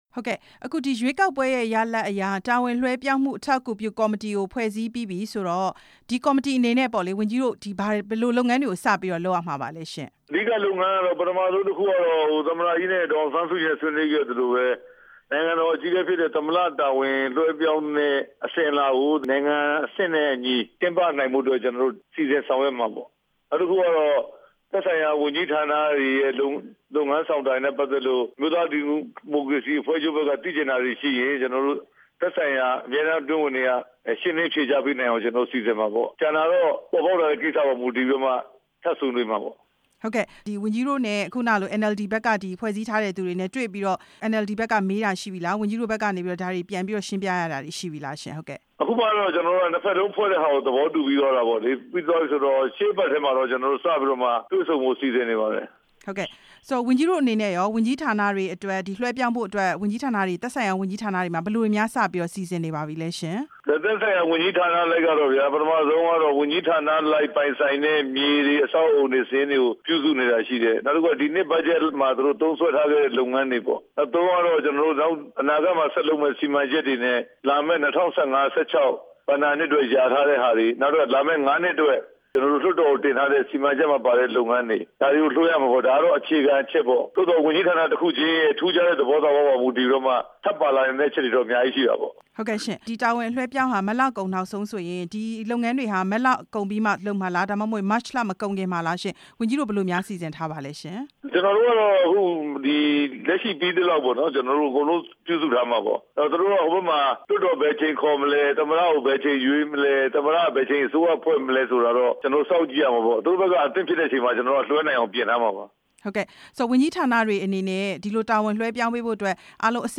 နိုင်ငံတော် အကြီးအကဲ တာဝန်လွဲှပြောင်းပေးနိုင်ရေး ဦးရဲထွဋ်နဲ့ မေးမြန်းချက်